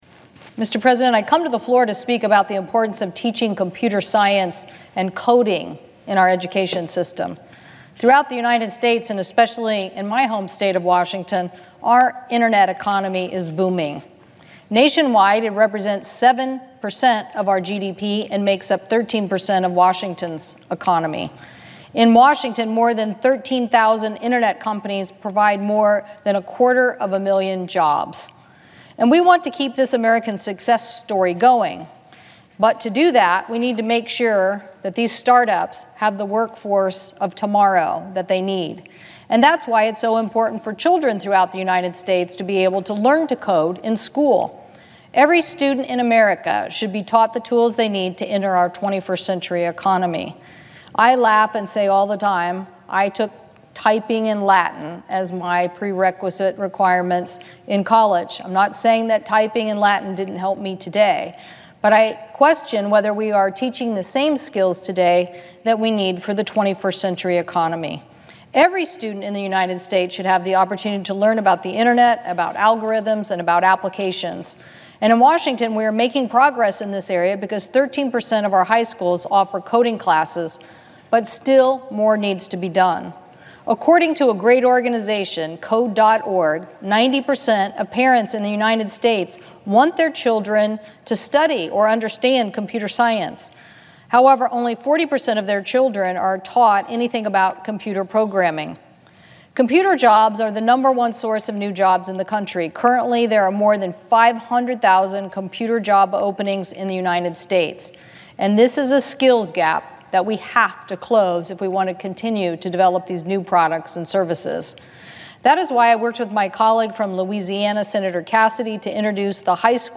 perkins-coding-floor-speech&download=1